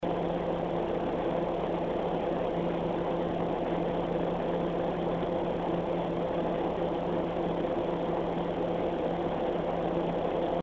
Xigmatek have equipped the HDT-S1284EE heatsink with a 120mm PWM fan set on vibration absorbing rubber posts. At full speed it produces a moderate amount of noise, at low speed it's very quiet.
frostytech acoustic sampling chamber - full speed
standard waveform view of a 10 second recording. click on the headphones icon to listen to an mp3 recording of this heatsink in operation. the fan is rotating at 1500 rpm.
Xigmatek HDT-S1284EE high 44.2 dB Intel/AMD